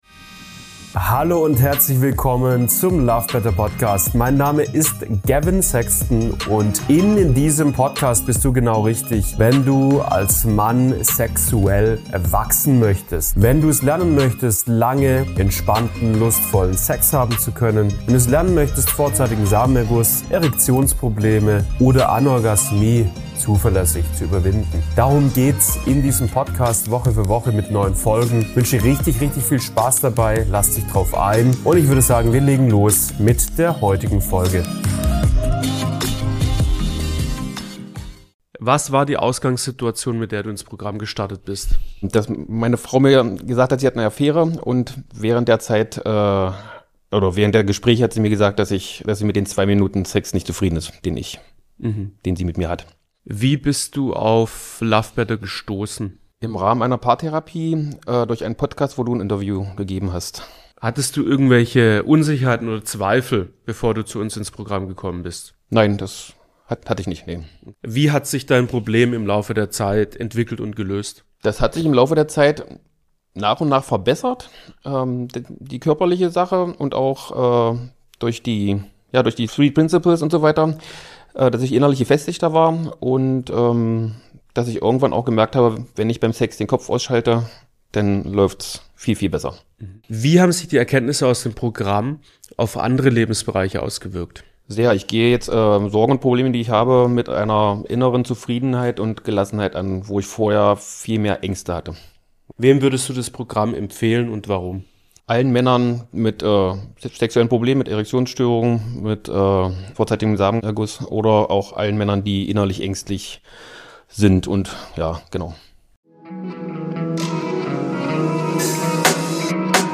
Vorzeitigen Samenerguss & Leistungsdruck überwunden | Klienteninterview